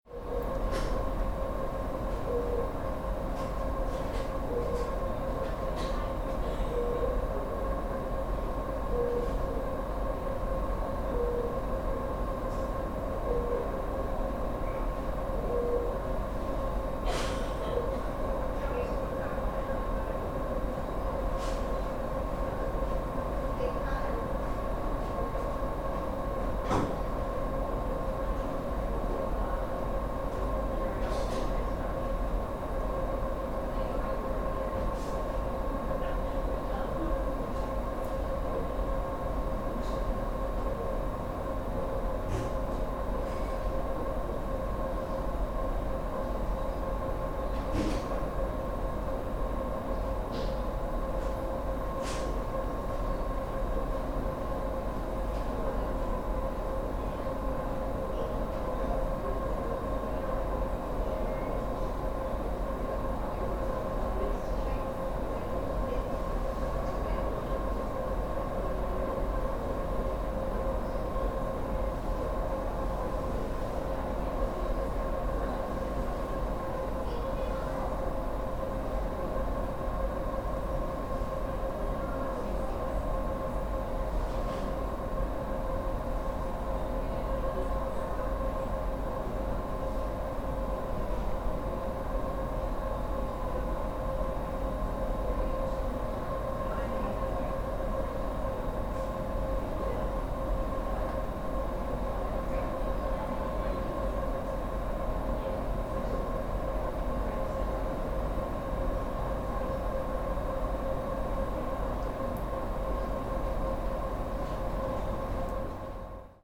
ambience.mp3